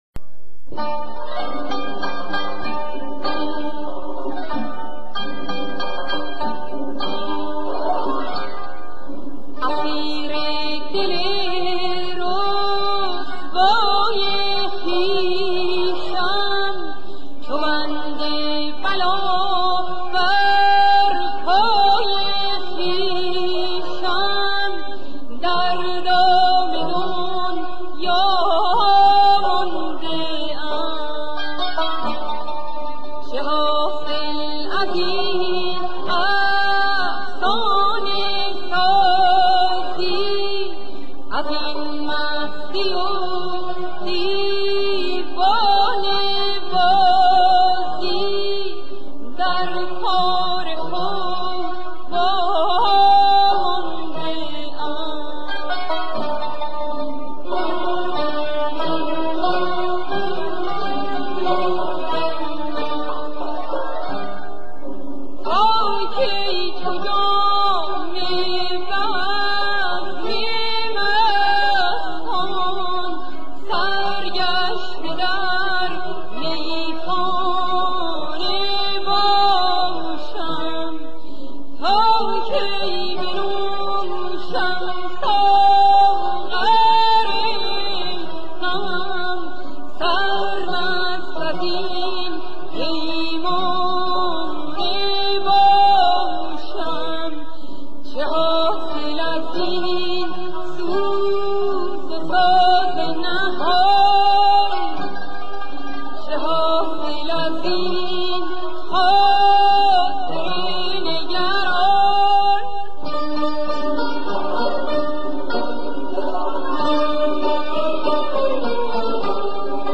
قدرت صدا تو نویز صدا کمتر بچشم میاد.
عجیب این آهنگ سوزناکه